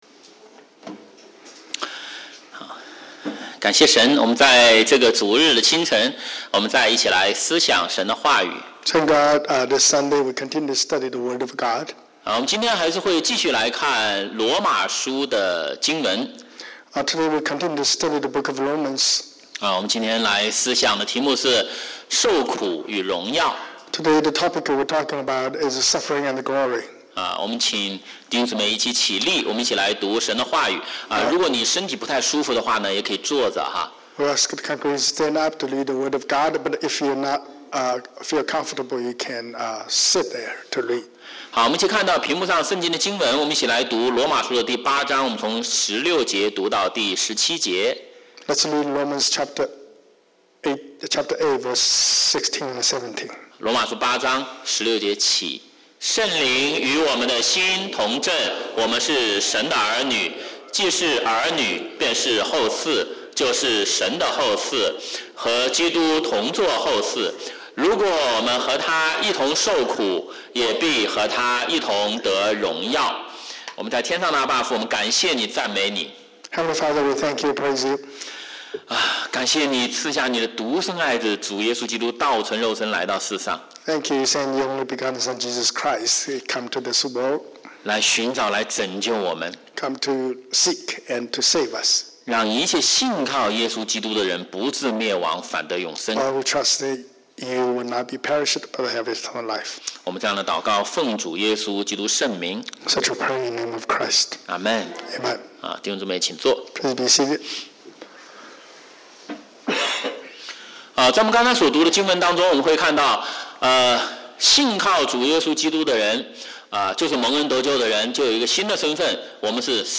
華語主日崇拜講道錄音